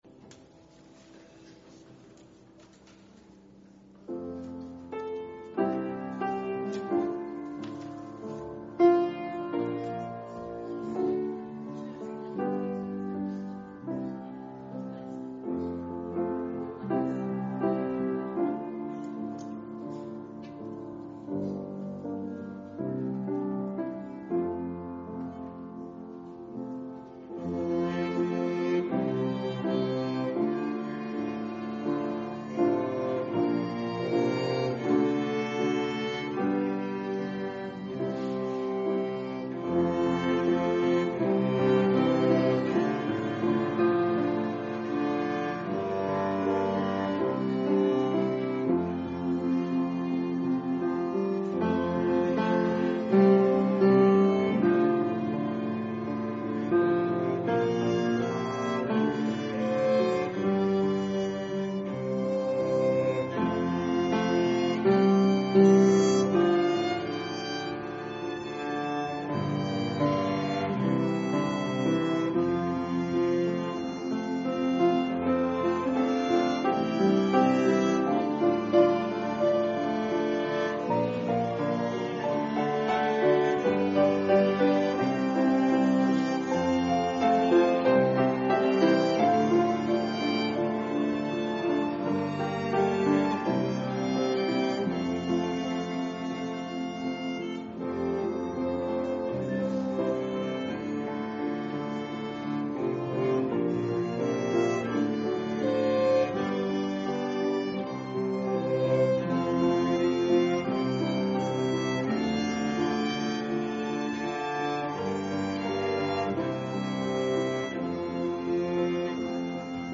Worship leader